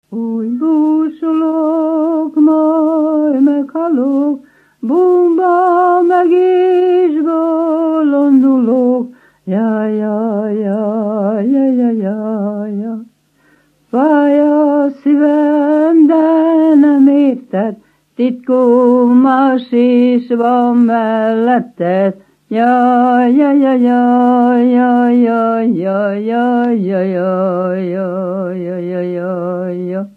Erdély - Udvarhely vm. - Gagy
Műfaj: Keserves
Stílus: 4. Sirató stílusú dallamok
Szótagszám: 8.8.8.8
Kadencia: X (X) X 1